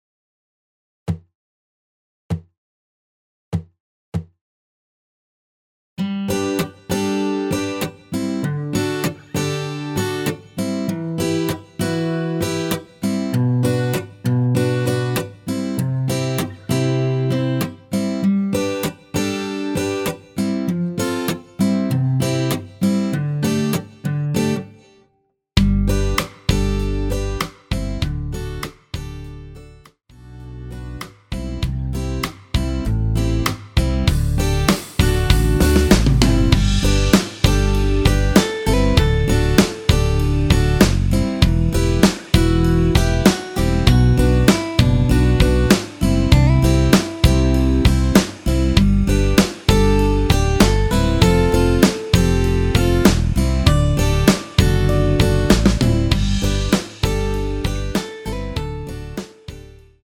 원키에서(+5)올린 MR이며 노래가 바로 시작 하는 곡이라 전주 만들어 놓았습니다.
앞부분30초, 뒷부분30초씩 편집해서 올려 드리고 있습니다.
중간에 음이 끈어지고 다시 나오는 이유는